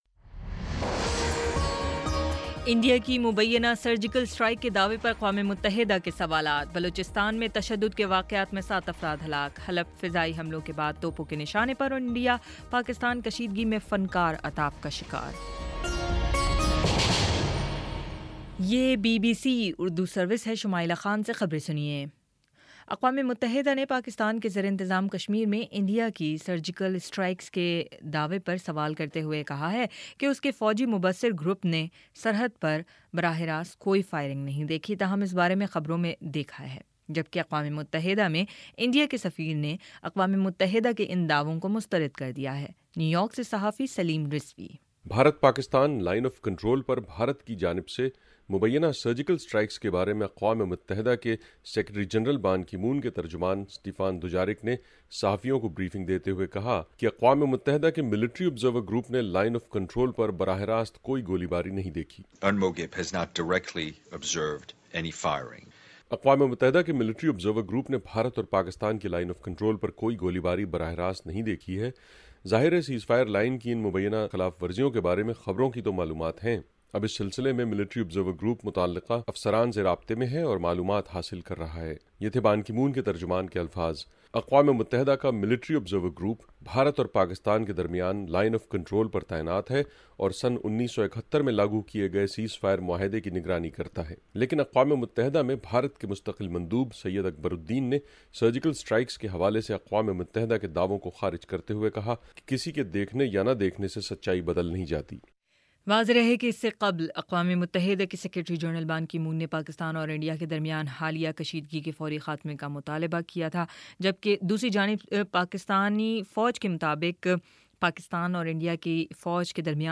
اکتوبر 01 : شام چھ بجے کا نیوز بُلیٹن